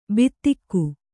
♪ bittikku